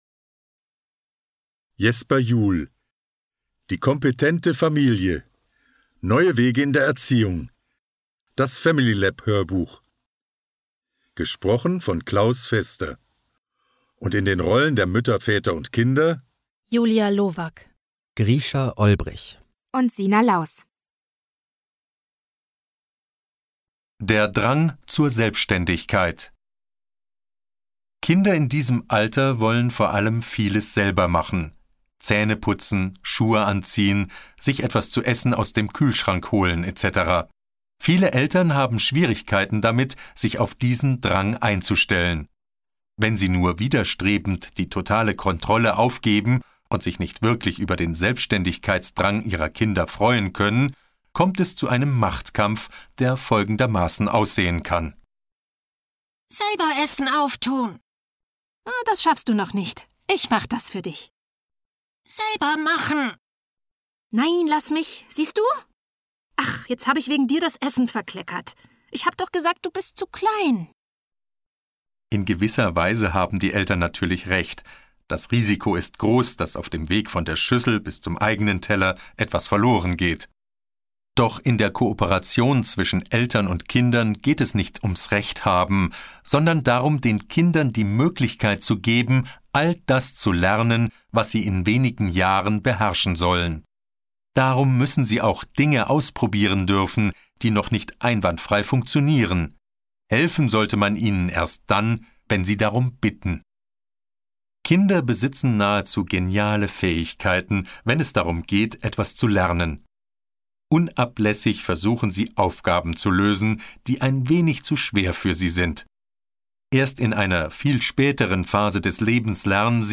Neue Wege in der Erziehung. Das familylab-Hörbuch